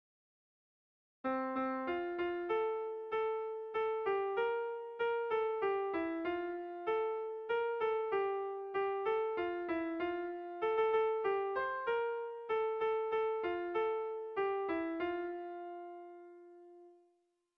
Irrizkoa
Kopla handia
ABD